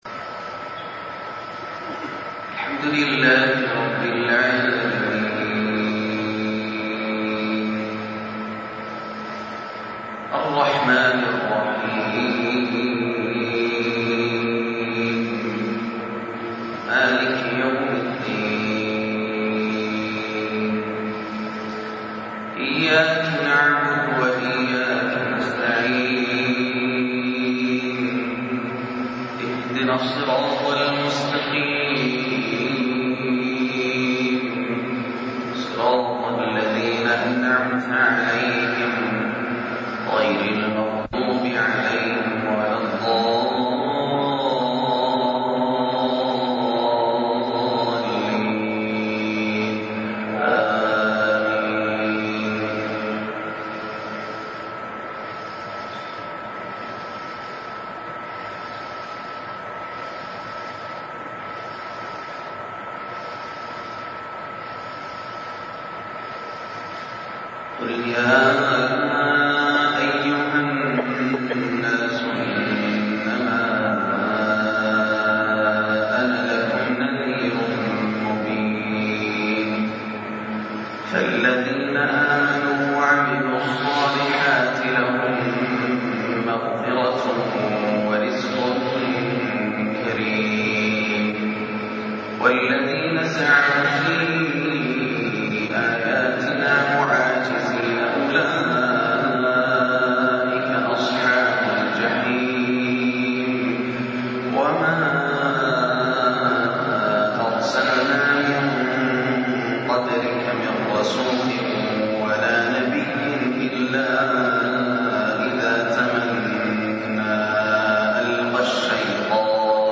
( الملك يومئذ لله ) ماتيسر من سورة الحج - فجرية روووعة > عام 1424 > الفروض - تلاوات ياسر الدوسري